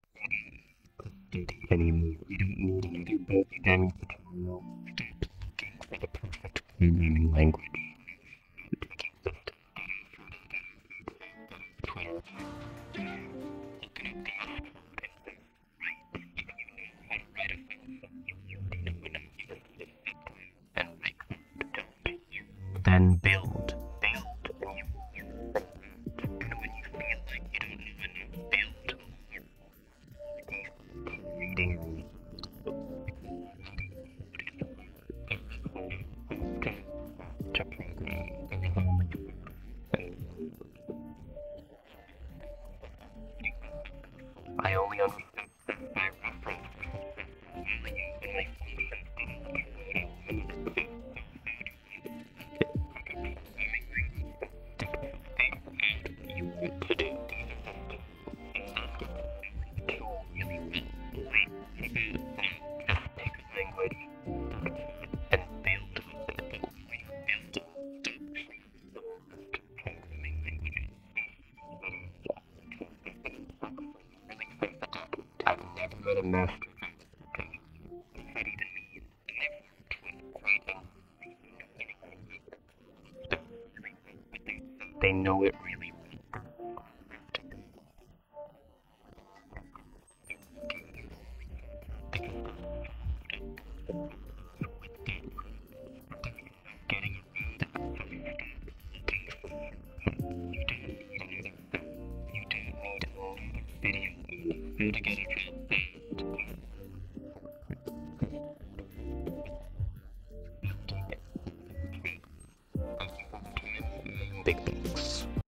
audio_Instruments.wav